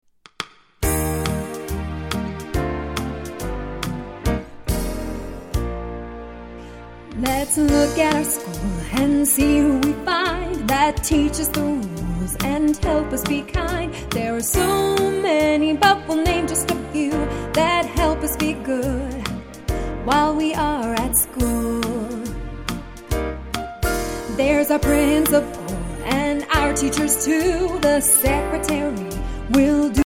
song clip